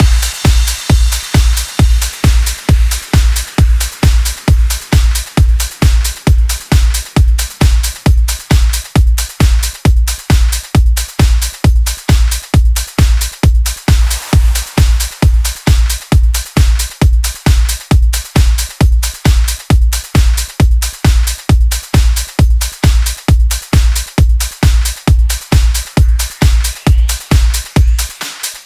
VTS1 Lovely Day Kit Drums & Perc